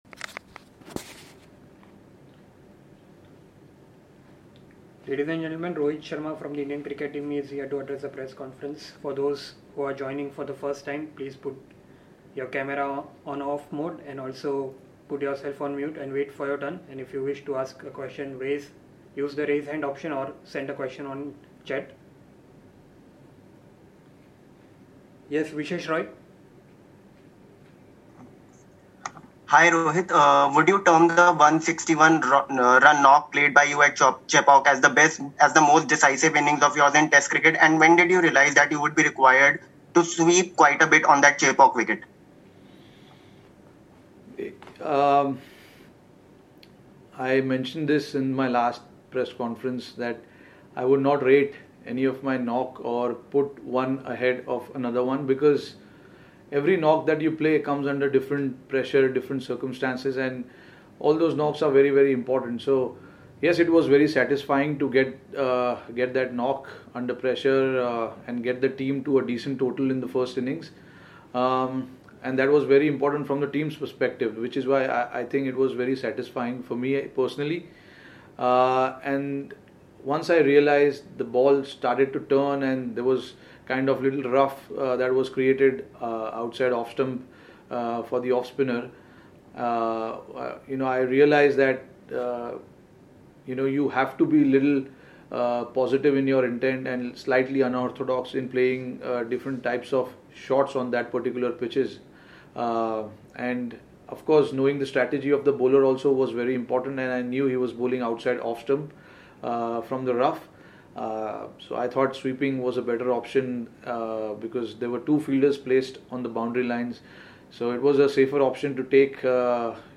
Rohit Sharma addressed a virtual press conference ahead of the third day-night Paytm pink-ball Test in Ahmedabad.